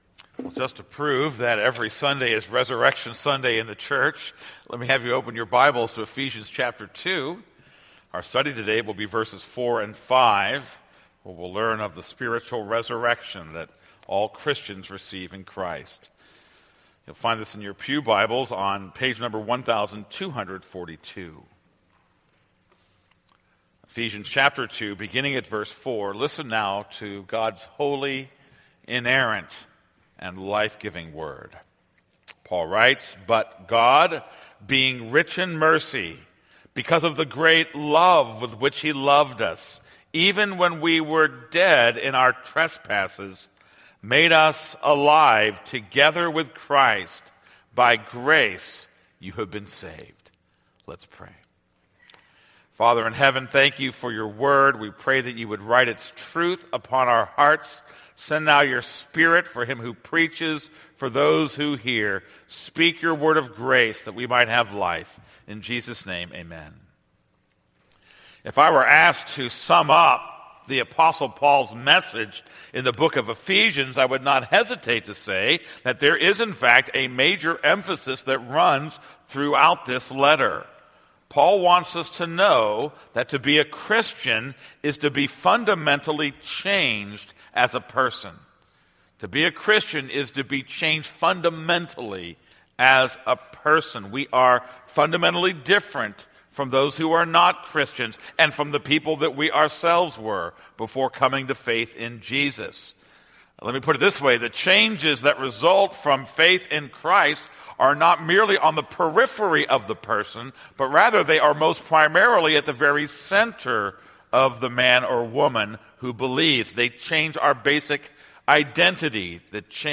This is a sermon on Ephesians 2:4-5.